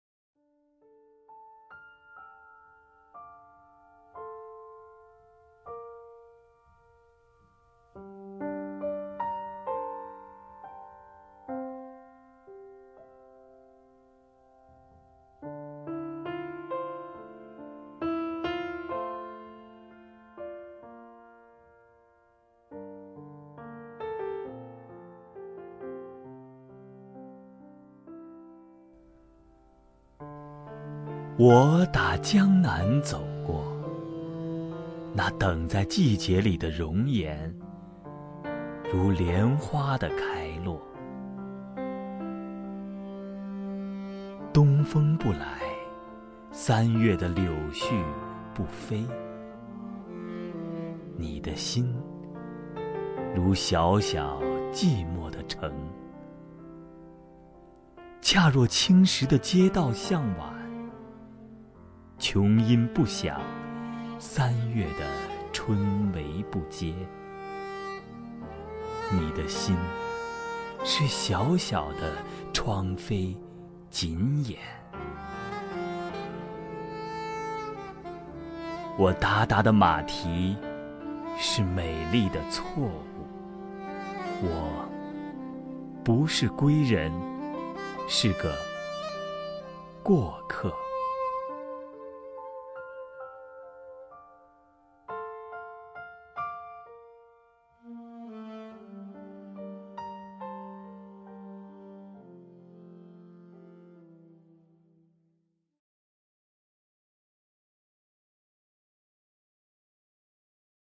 首页 视听 名家朗诵欣赏 康辉
康辉朗诵：《错误》(郑愁予)　/ 郑愁予